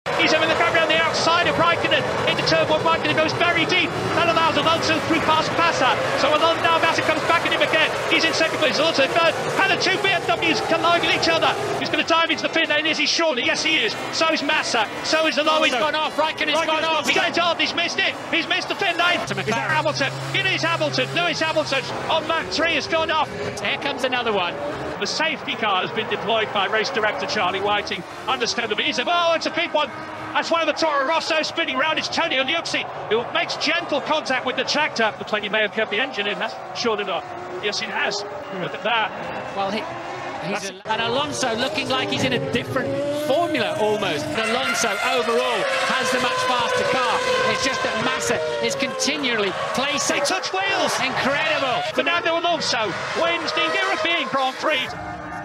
Enjoy race highlights of the Formula 1 2007 European Grand Prix at the Nurburgring, With special commentary from F1 legend Murray Walker.